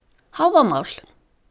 Please note that the accent is always on the first syllable in Icelandic.